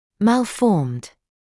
[ˌmæl’fɔːmd][ˌмэл’фоːмд]имеющий неправильную форму; деформированный; уродливый